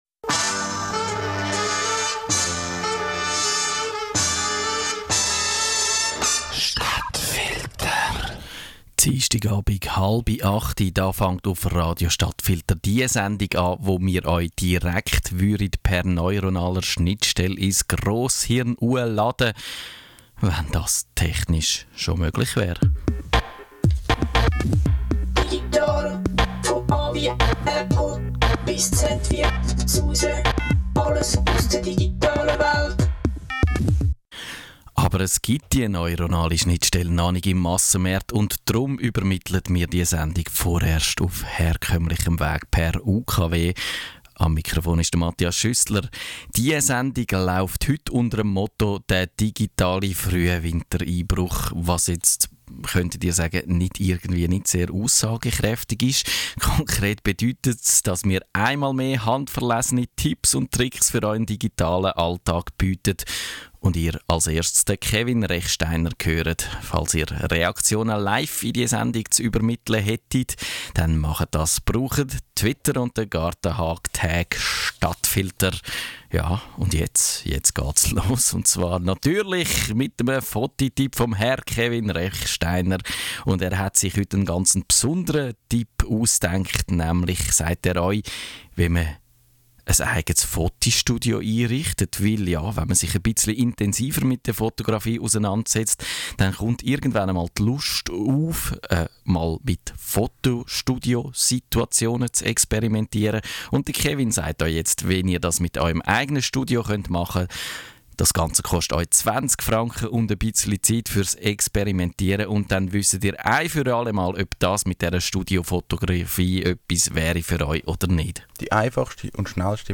Im Podcast hört ihr das ausführliche Interview